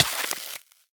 Minecraft Version Minecraft Version 1.21.5 Latest Release | Latest Snapshot 1.21.5 / assets / minecraft / sounds / entity / player / hurt / freeze_hurt3.ogg Compare With Compare With Latest Release | Latest Snapshot
freeze_hurt3.ogg